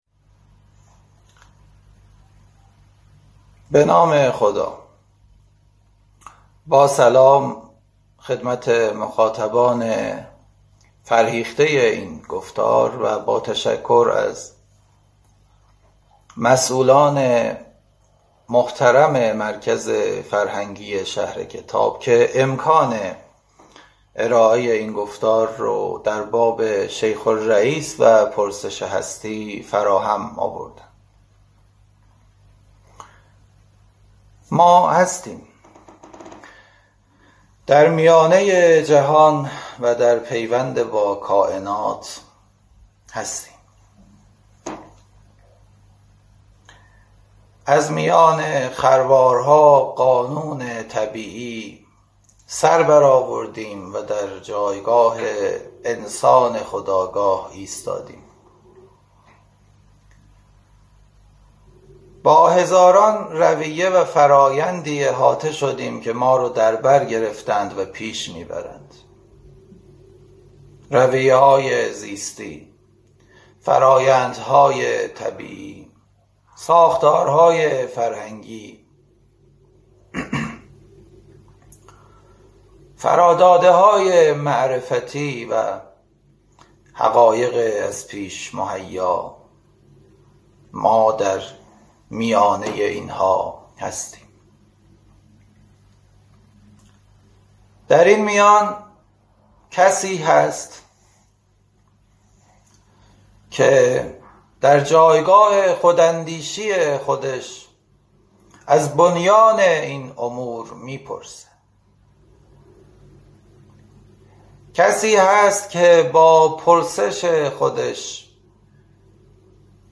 این درس‌گفتار به صورت مجازی از اینستاگرام شهر کتاب پخش شد. انسان است که از هستی می‌پرسد و راه می‌جوید.